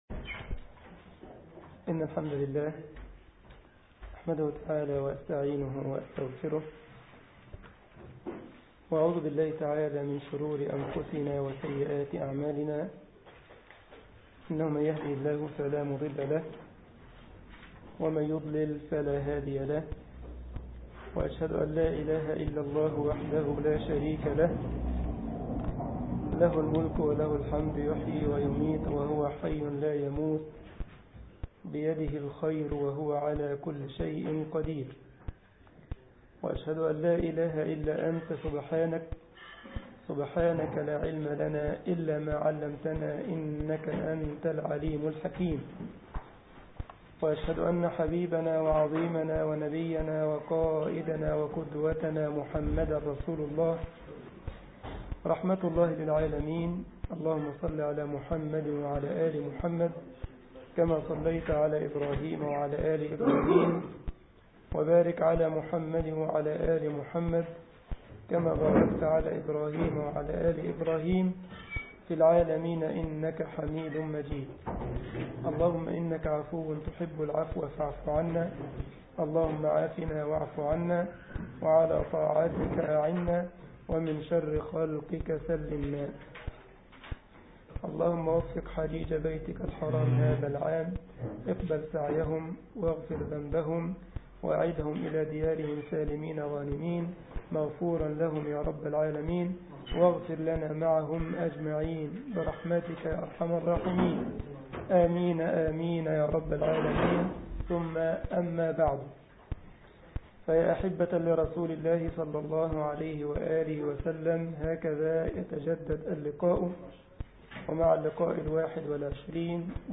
مسجد الجمعية الإسلامية بالسارلند ـ ألمانيا درس الأحد